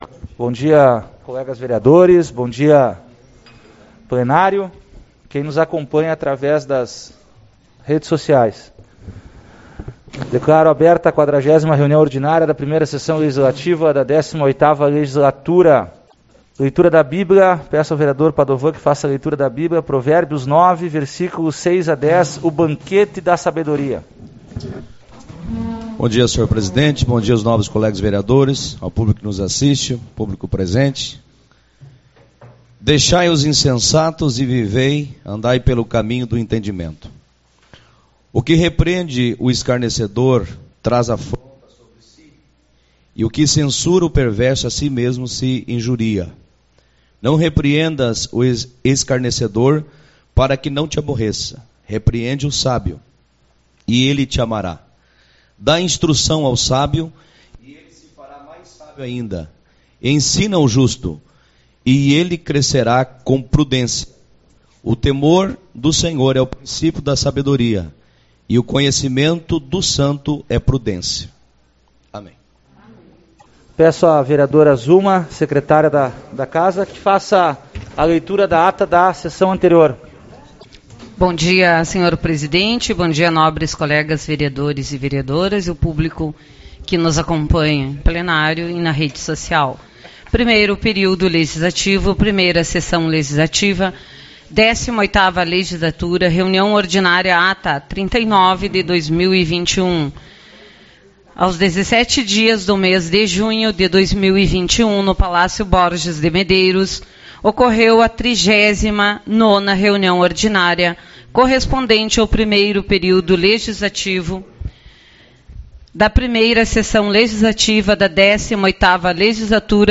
22/06 - Reunião Ordinária